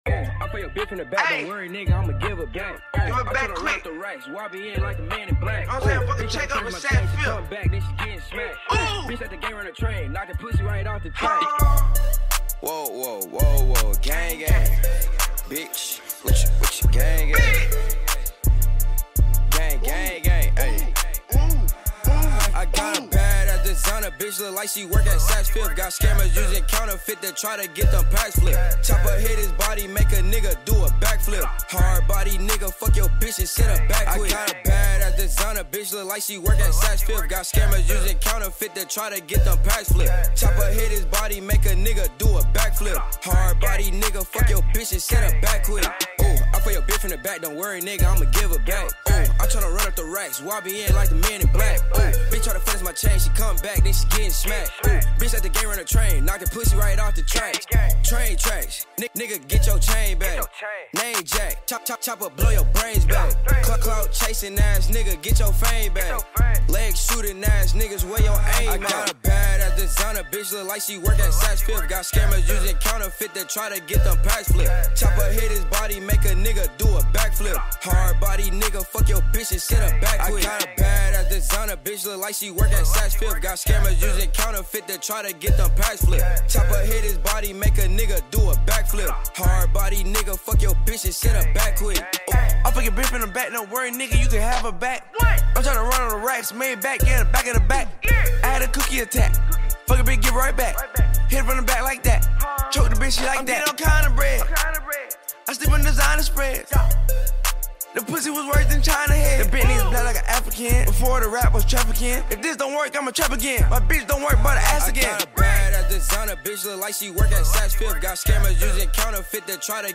энергичная хип-хоп композиция